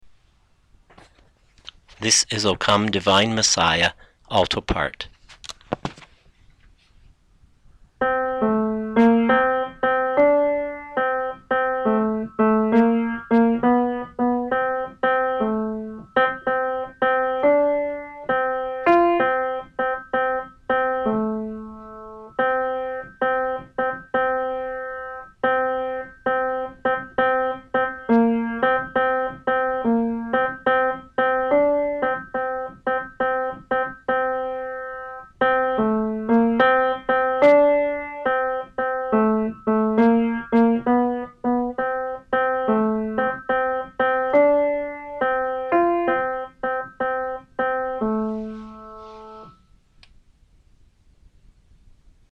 O Come Divine Messiah - Alto 2012-10-20 Choir